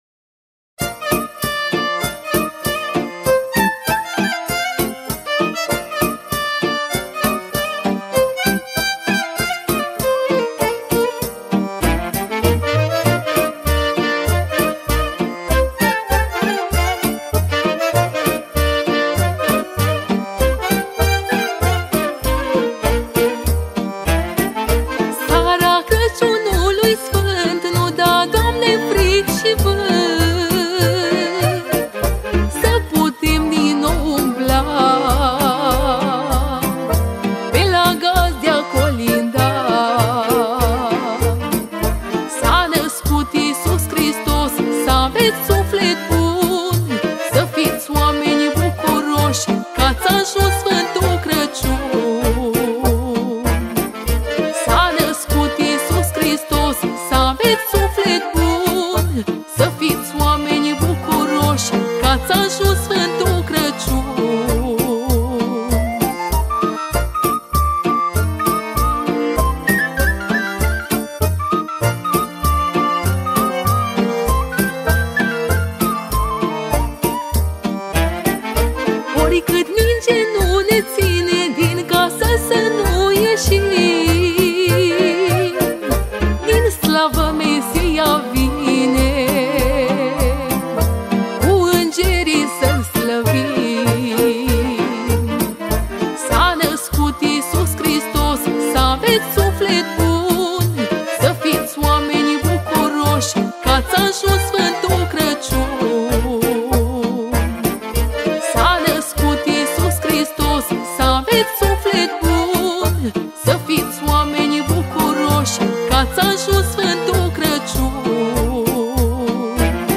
Data: 12.10.2024  Colinde Craciun Hits: 0